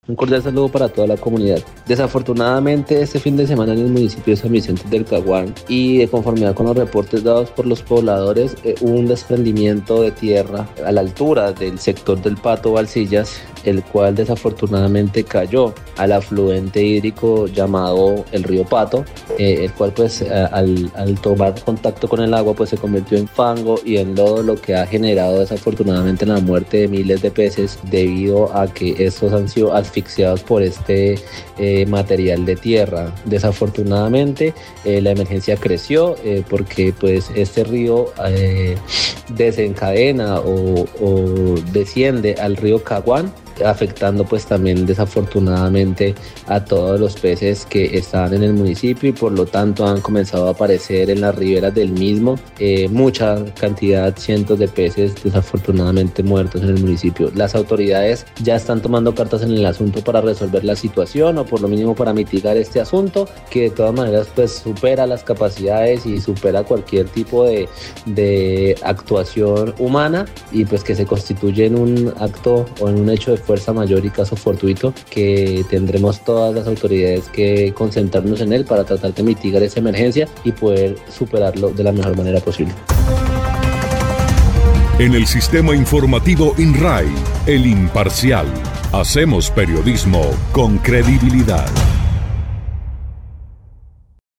Camilo Losada, personero del municipio de San Vicente del Caguan, dijo que la emergencia tuvo su origen el pasado fin de semana, cuando se presentó un desprendimiento de tierra a la altura del sitio conocido como El Pato Balsillas, generando la muerte por asfixia de los peces.
04_PERSONERO_CAMILO_LOZADA_PECES.mp3